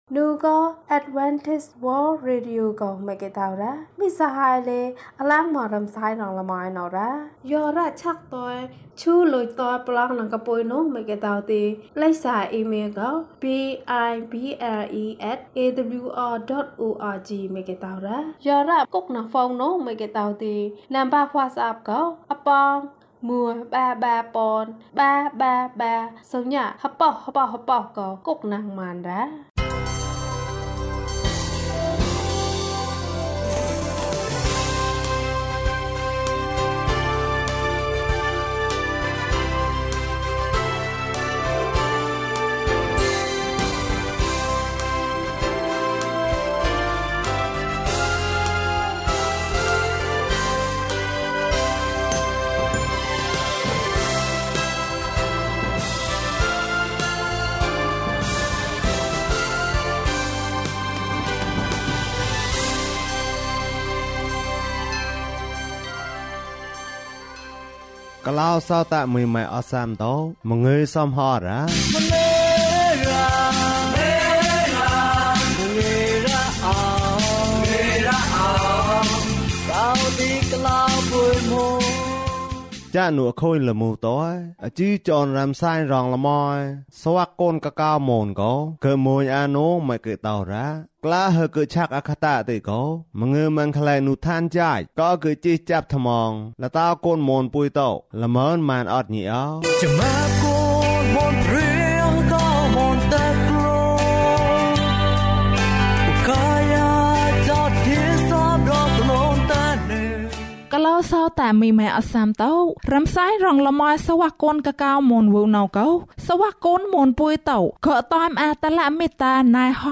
ဘုရားသခင်ကို ယုံကြည်ပါ၊ ကျန်းမာခြင်းအကြောင်းအရာ။ ဓမ္မသီချင်း။ တရားဒေသနာ။